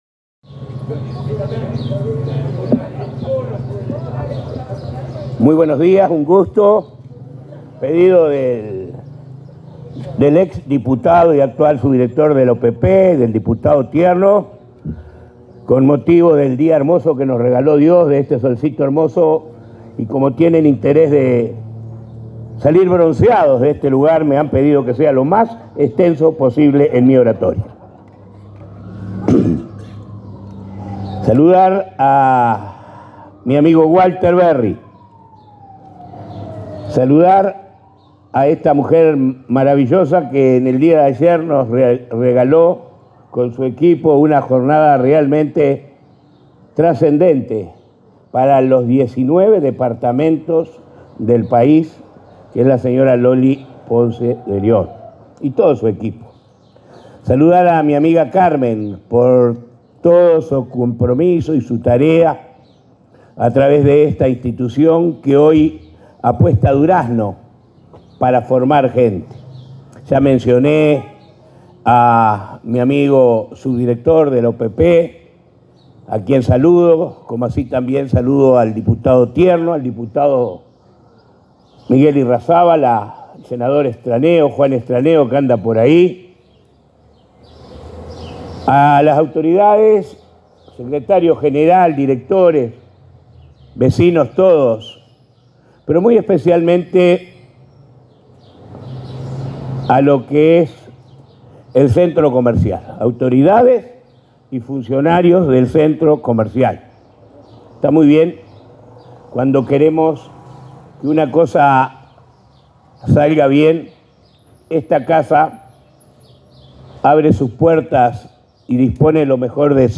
Conferencia de prensa por la inauguración de Centro Pyme en Durazno
Participaron la presidenta de ANDE, Cármen Sánchez; el subsecretario del Ministerio de Industria, Energía y Minería, Walter Verri; la impulsora de Sembrando, Lorena Ponce de León, y el intendente departamental, Carmelo Vidalín, entre otras autoridades.